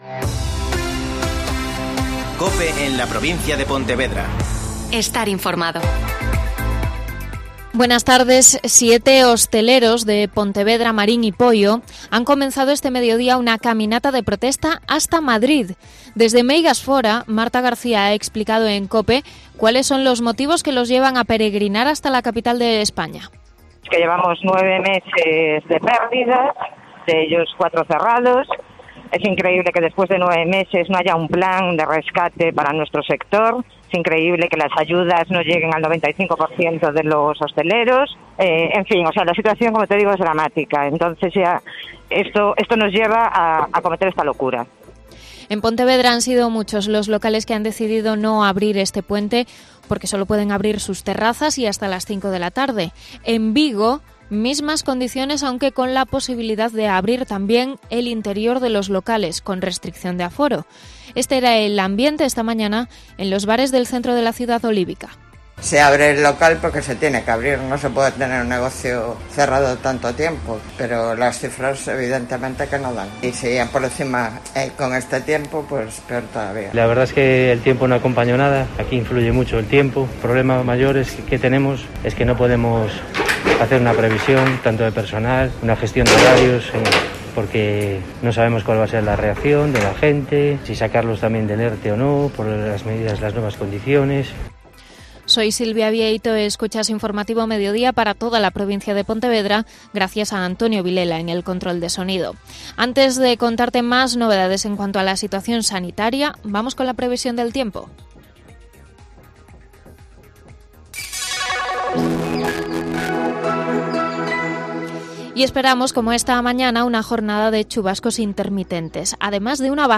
Mediodía COPE Vigo (Informativo 14:20h)